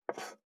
545魚切る,肉切りナイフ,まな板の上,包丁,
効果音厨房/台所/レストラン/kitchen食器食材